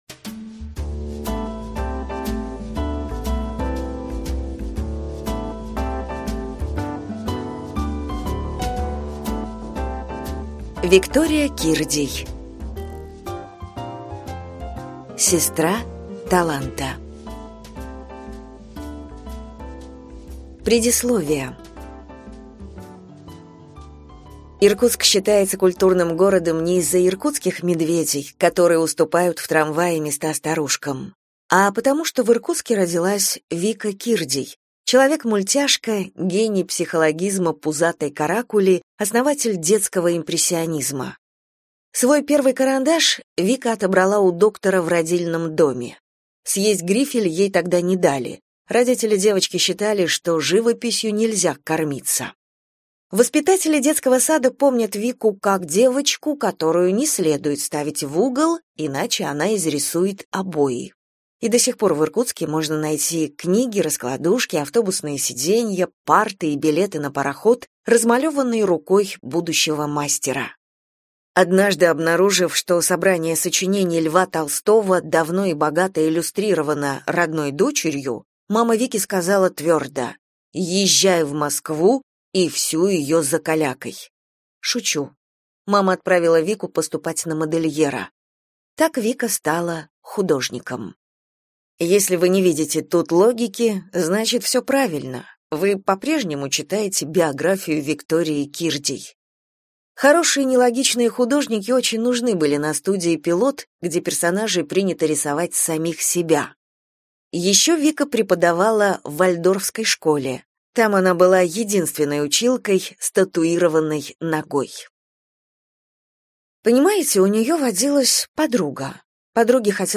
Аудиокнига Сестра таланта | Библиотека аудиокниг
Прослушать и бесплатно скачать фрагмент аудиокниги